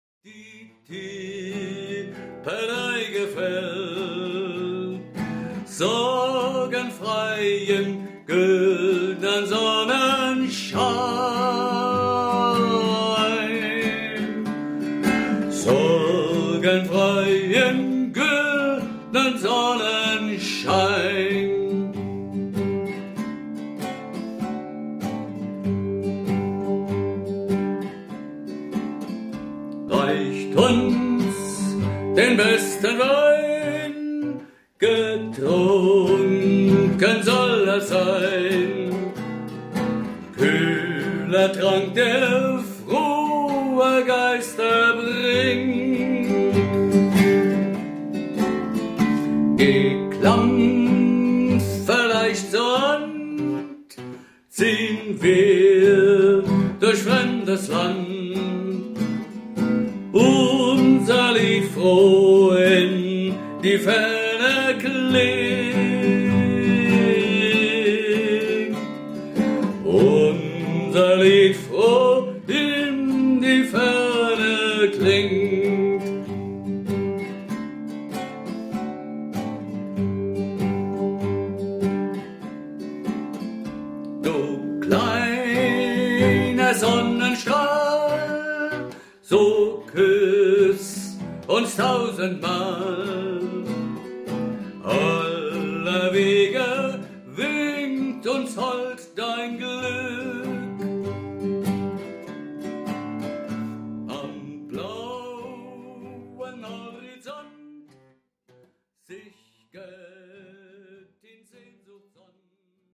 Es entstand ein Ohrwurm in modernem Musikstil.